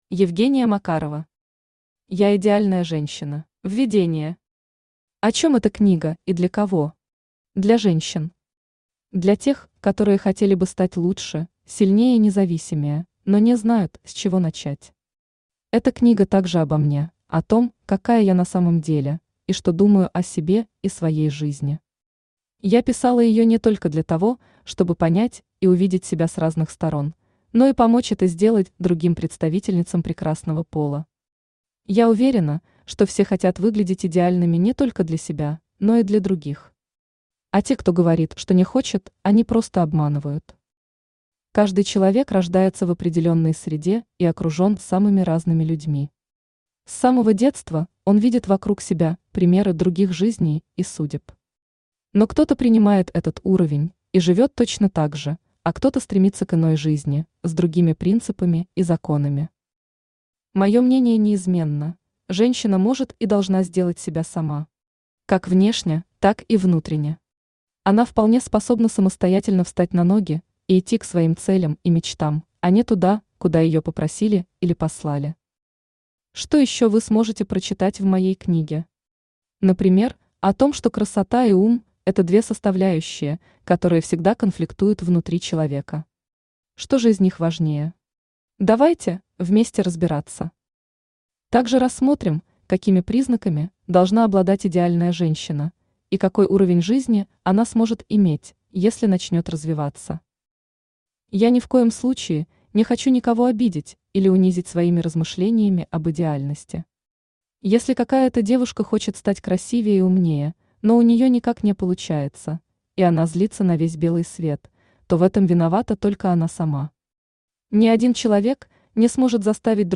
Аудиокнига Я идеальная женщина!
Автор Евгения Сергеевна Макарова Читает аудиокнигу Авточтец ЛитРес.